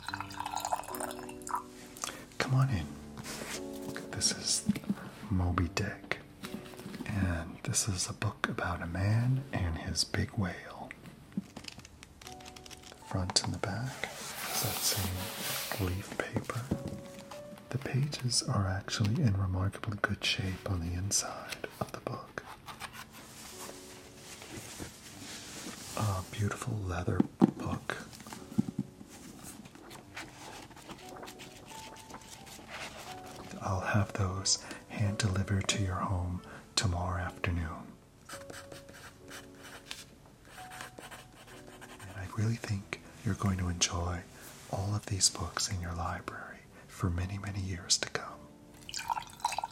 Antique Book Dealer (Talking) Library sound effects free download
Antique Book Dealer (Talking) Library ASMR 📚 I love the sounds that books and paper make. So soothing, relaxing and cozy.